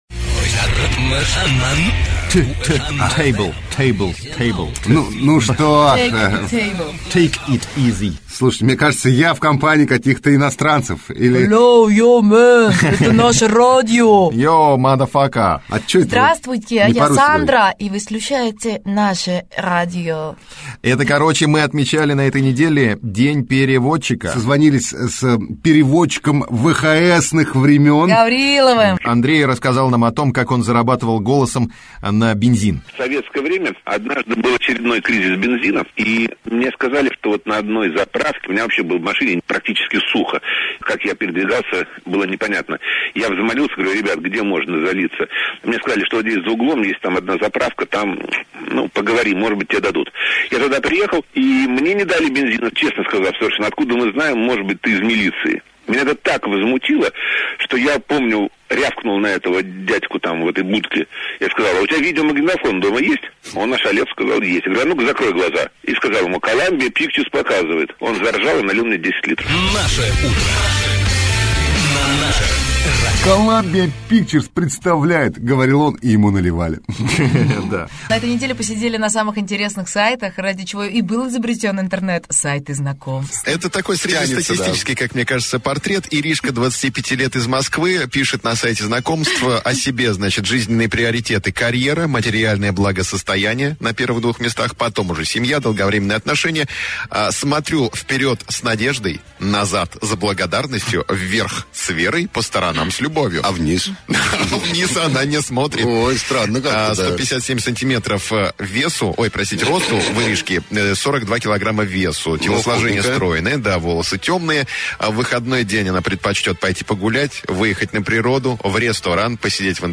| Теги: диджей, Наше радио, радиоведущий, запись эфира, Наше утро, утреннее шоу